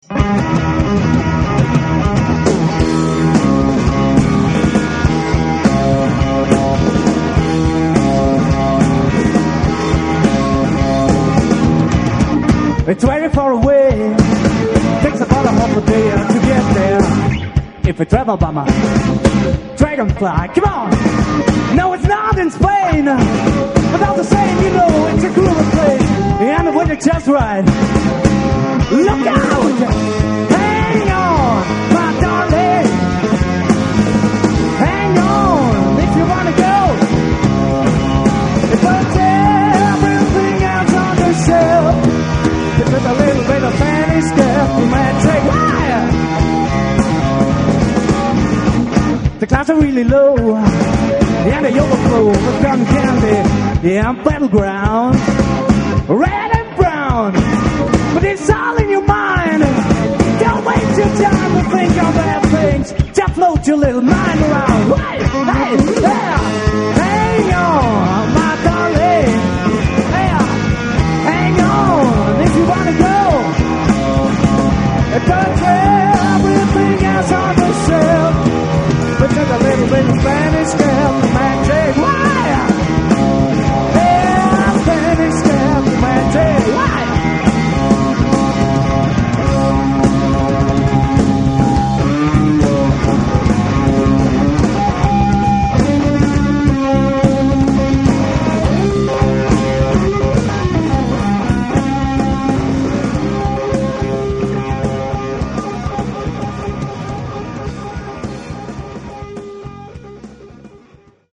Live in der Mehrzweckhalle Chur 1996, Vocals & Bass